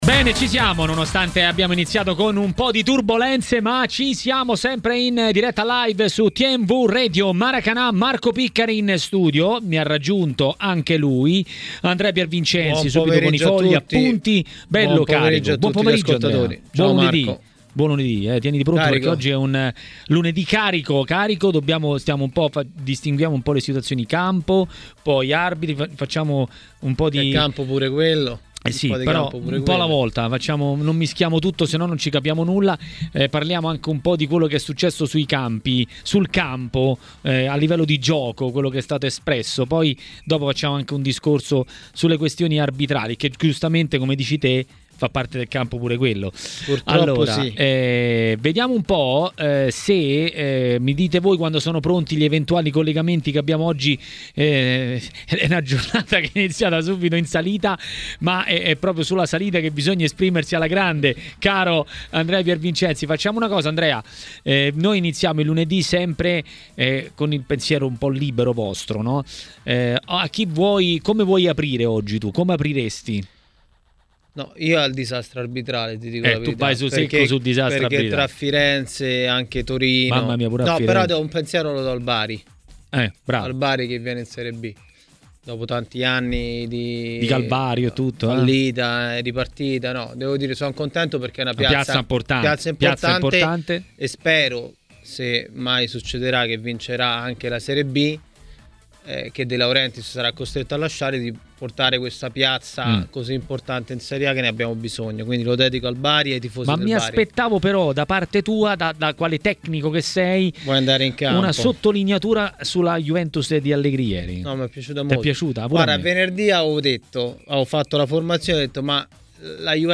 A Maracanà, nel pomeriggio di TMW Radio, è arrivato il momento del giornalista ed ex calciatore Stefano Impallomeni, che ha parlato della giornata di Serie A.